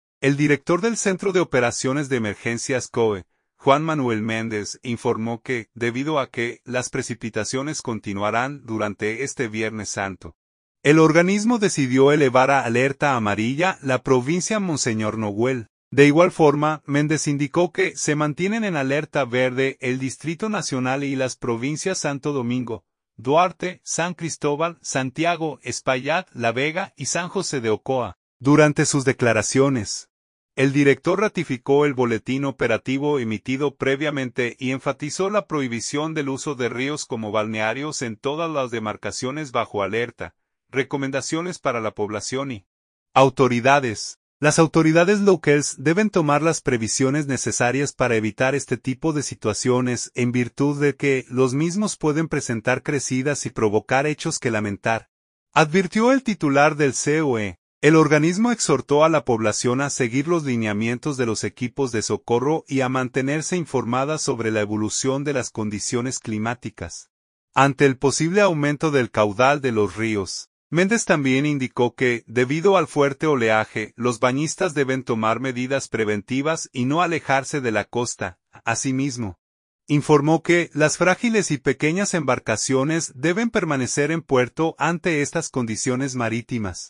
Durante sus declaraciones, el director ratificó el boletín operativo emitido previamente y enfatizó la prohibición del uso de ríos como balnearios en todas las demarcaciones bajo alerta.